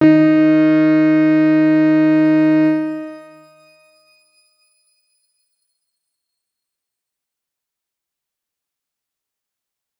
X_Grain-D#3-pp.wav